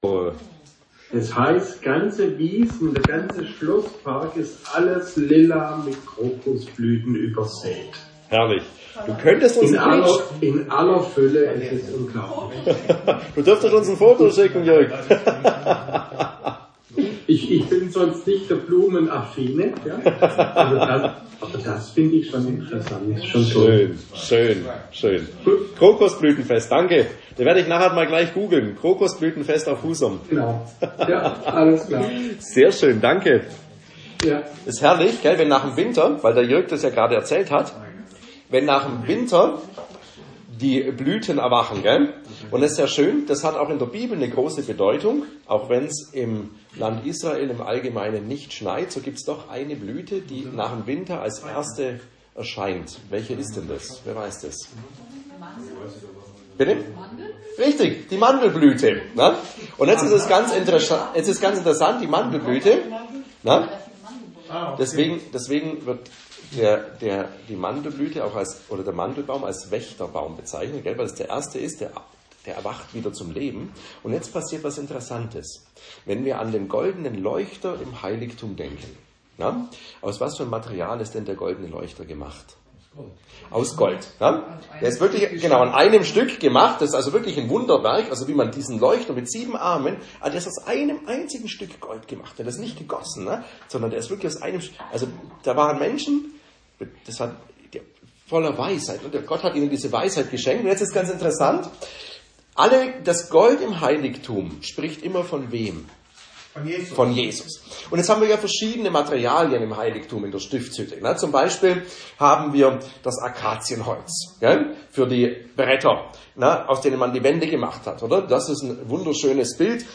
Aufzeichnungen der Bibelstunden vom Freitagabend / Ruderatshofen: Dateien zum download mit der rechten Maustaste anklicken und dann auf "Ziel speichern unter..." klicken...! - 2024 / 25 - Bibelstunden zum Matthäusevangelium : 1.